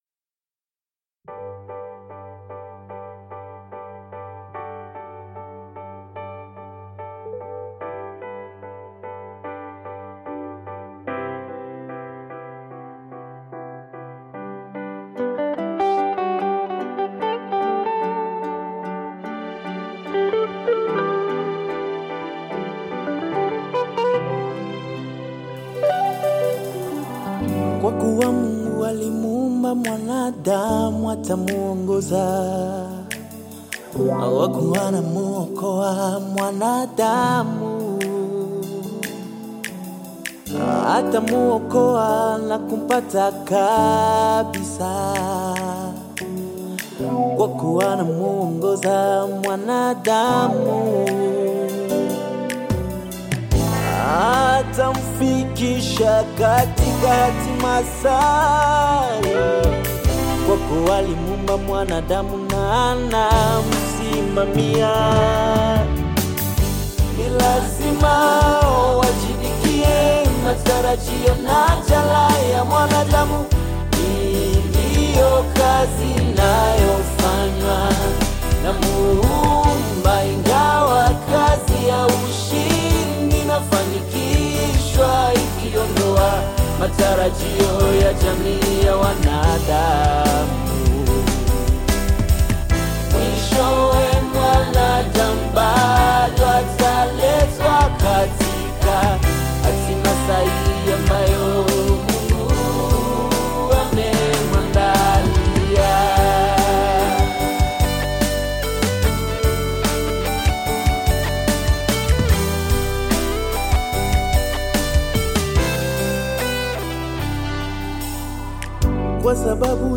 Categories: Hymns of God's Words